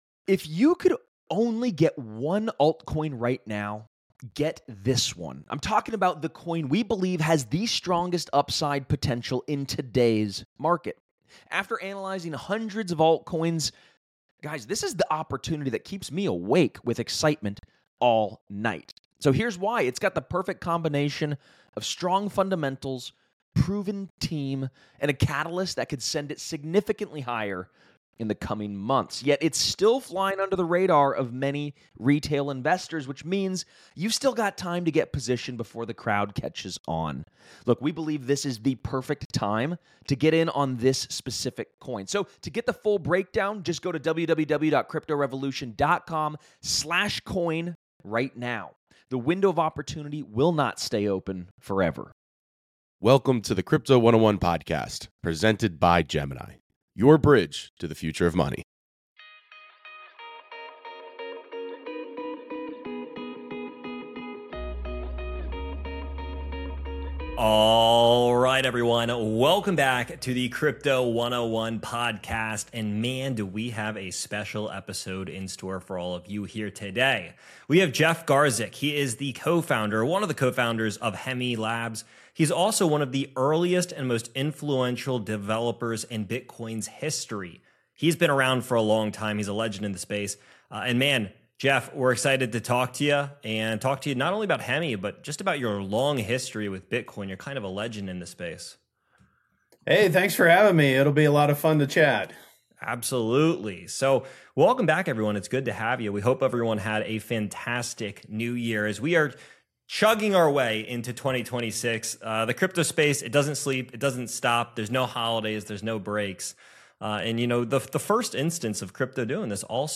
Is a P2P Sharing Economy Practical? a Debate w